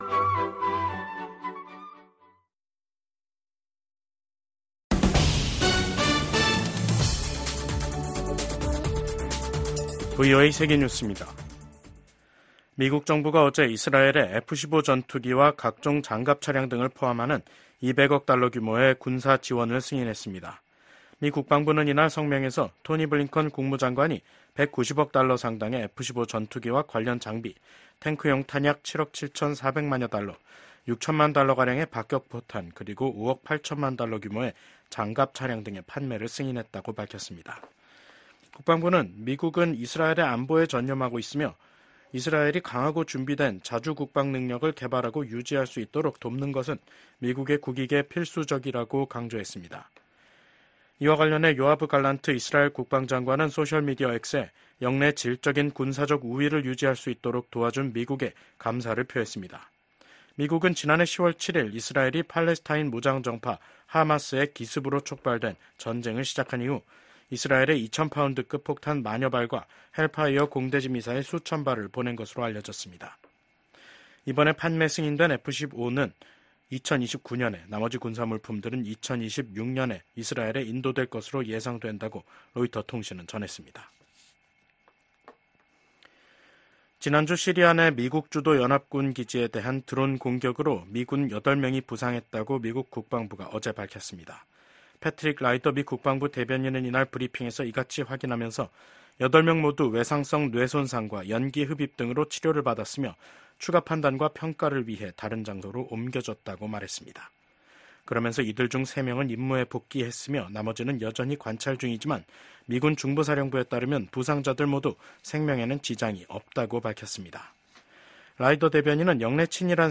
VOA 한국어 간판 뉴스 프로그램 '뉴스 투데이', 2024년 8월 14일 3부 방송입니다. 북한이 개성공단 내 철도 부속 건물 2개 동을 해체했습니다. 미 국무부는 북한이 러시아의 전쟁 수행을 지원하는 것을 좌시하지 않겠다고 밝혔습니다. 미국 국방부는 한국군의 전략사령부 창설 추진과 관련해 미한 동맹을 강조하며 긴밀하게 협력해 나갈 것이라고 밝혔습니다.